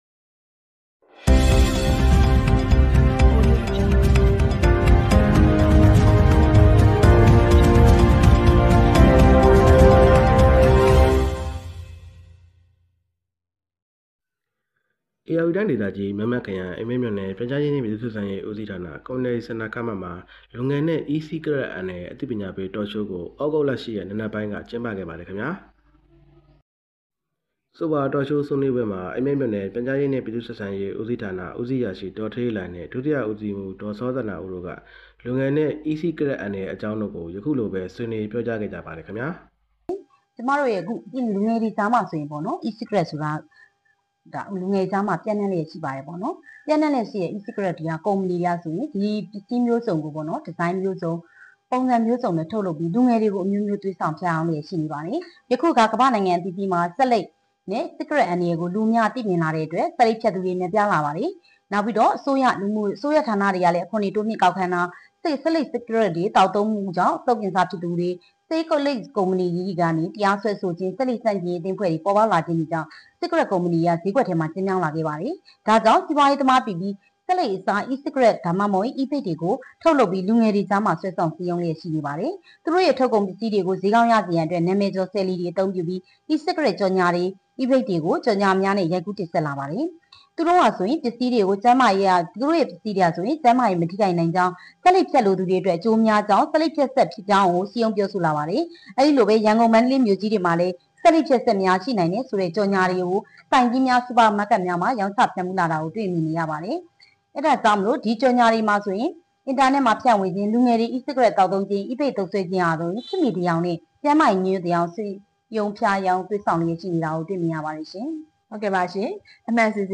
အိမ်မဲမြို့နယ် Community Centre ခန်းမတွင် လူငယ်နှင့်အီးစီးကရက်အန္တရာယ်အသိပည...